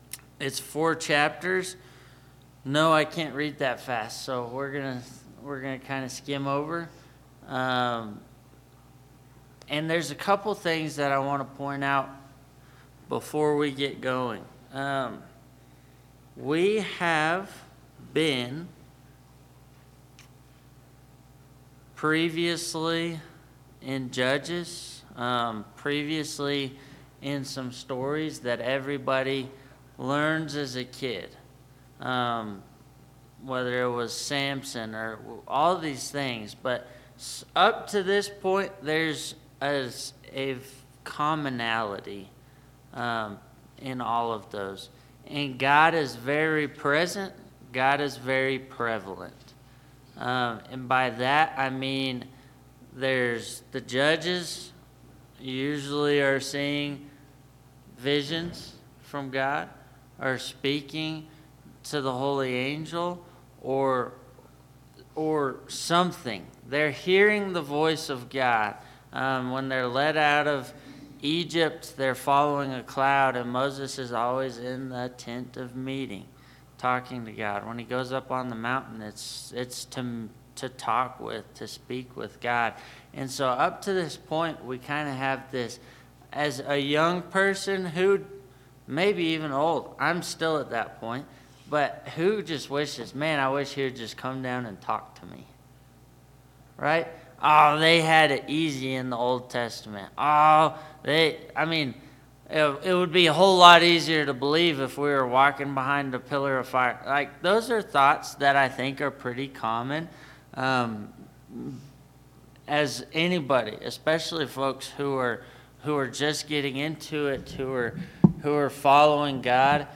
Bible Class 02/02/2025 - Bayfield church of Christ